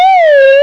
cartoons
slidedn.mp3